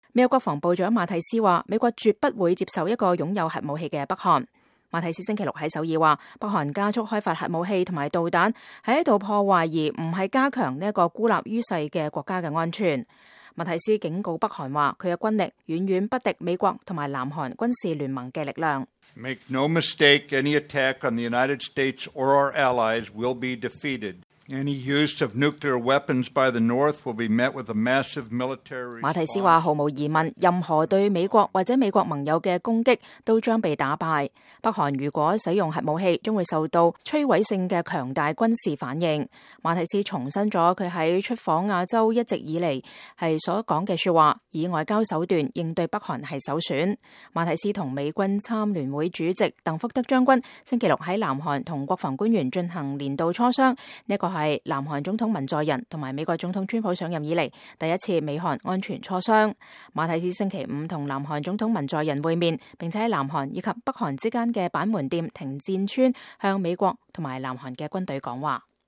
美國國防部長馬蒂斯在板門店停戰村對記者講話